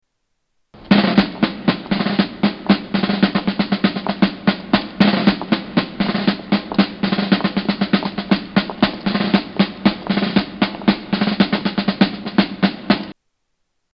Category: Movies   Right: Personal